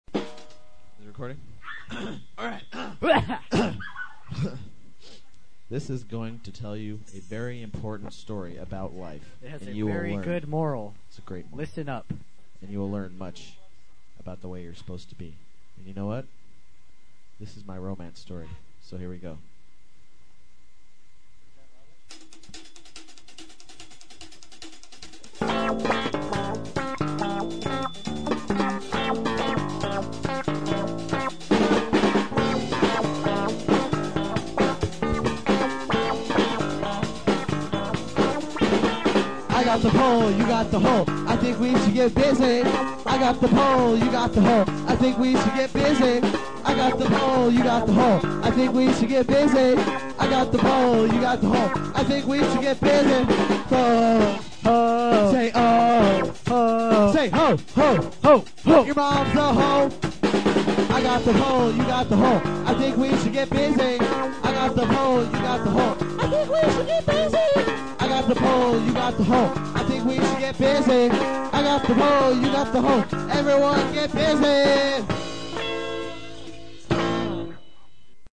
Guitar
Drums/Vocals
Bass/Back-up Vocals